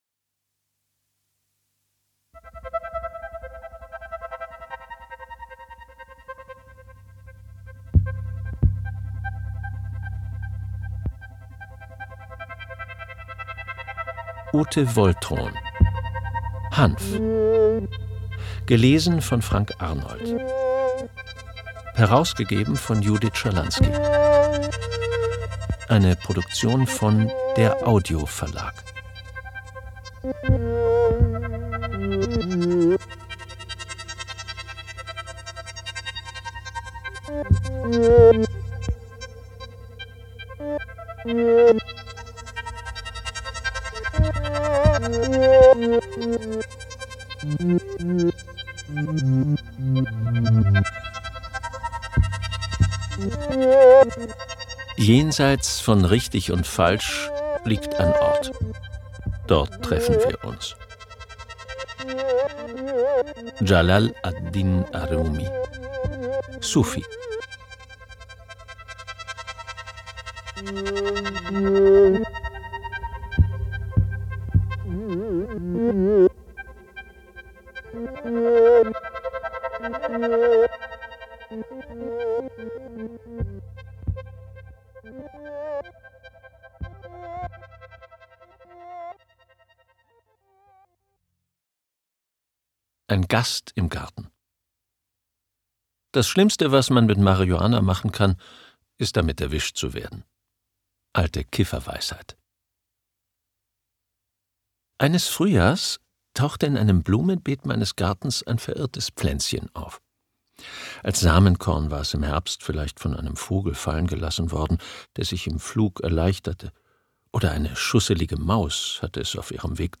Hörbuch: Hanf.
Hanf. Ein Portrait Ungekürzte Lesung